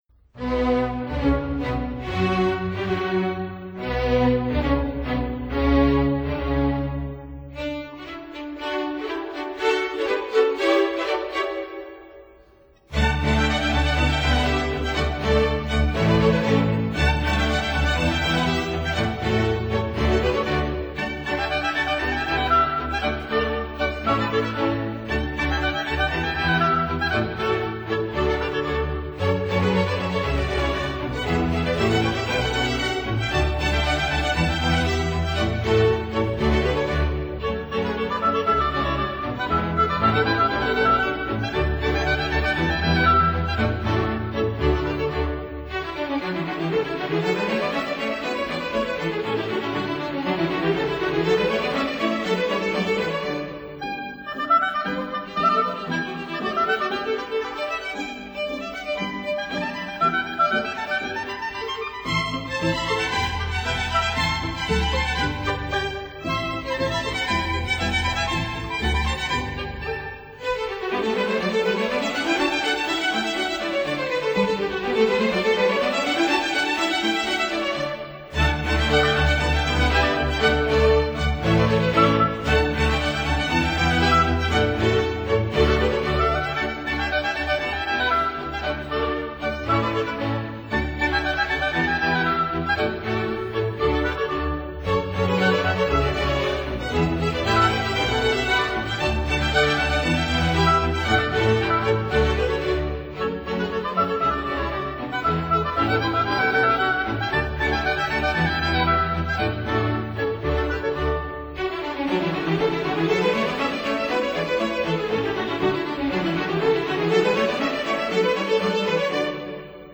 oboe
violin